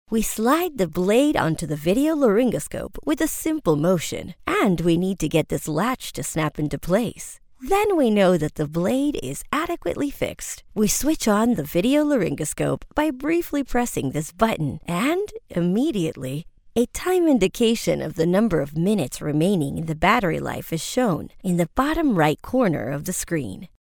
Voiceovers American English  female voice overs. Group A